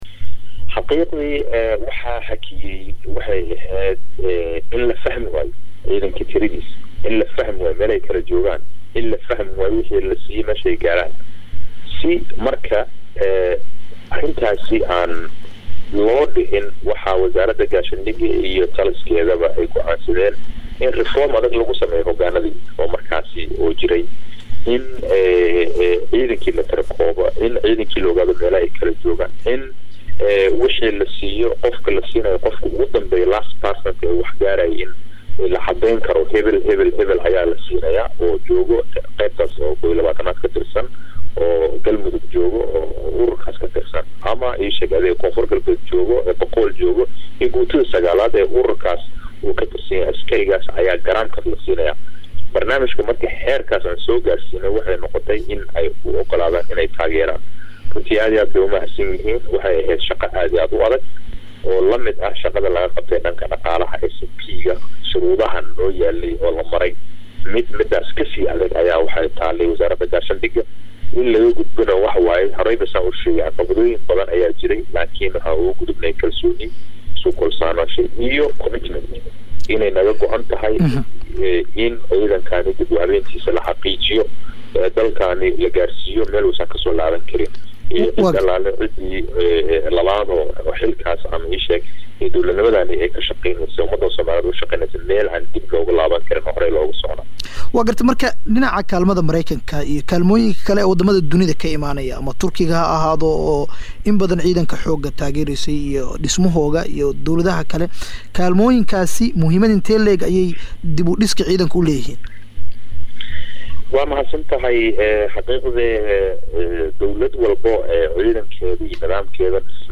Dhageyso qeybta koowaad ee Wareysiga Wasiirka Difaaca ee dalka